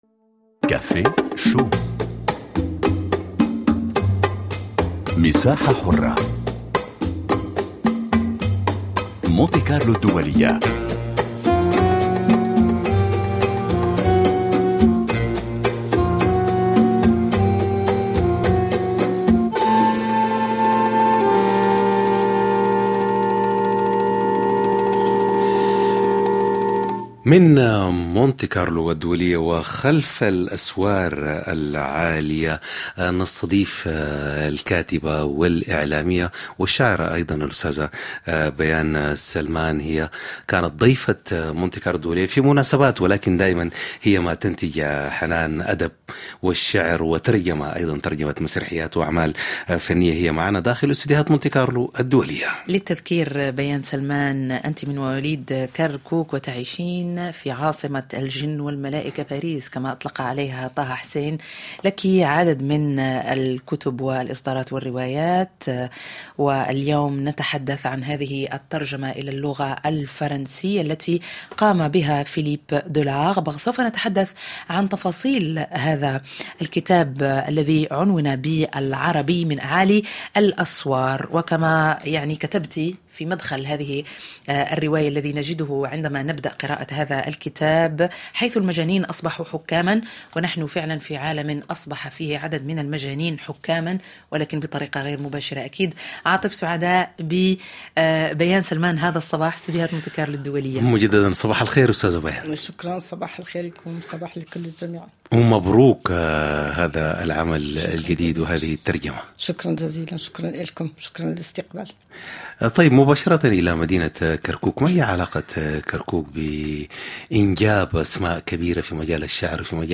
مجلة صباحية يومية يلتقي فيها فريق كافيه شو مع المستمعين للتفاعل من خلال لقاءات وفقرات ومواضيع يومية من مجالات مختلفة : ثقافة، فنون، صحة، مجتمع، بالاضافة إلى الشأن الشبابي عبر مختلف بلدان العالم العربي.